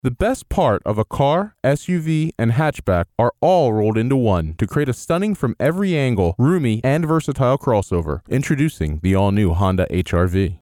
MXL 770 Condenser Microphone, Focusrite Scarlett 2i4 Audio Interface, DBX 286S Preamp/Processor, Adobe Audition Creative Cloud.
Dry Vocal Tracks
Honda-HRV-Dry.mp3